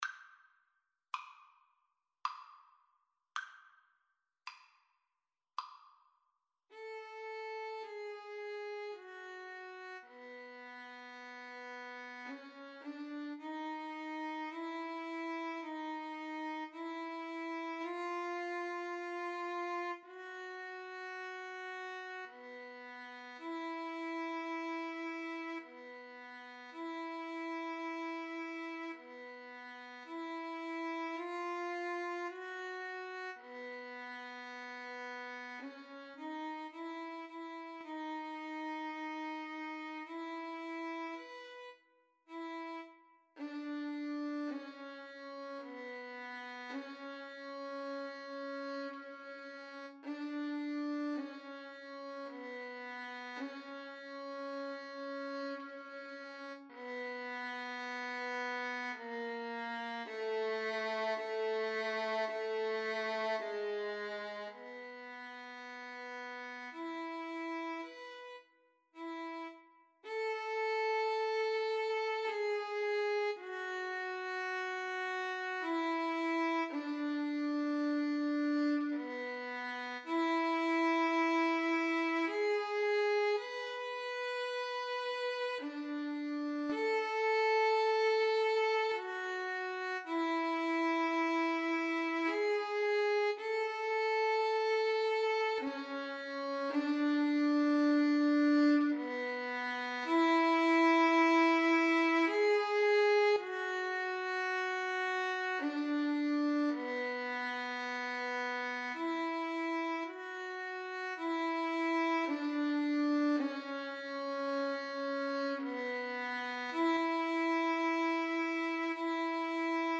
Violin 1Violin 2
3/4 (View more 3/4 Music)
Andante sostenuto ( = 54)
Classical (View more Classical Violin Duet Music)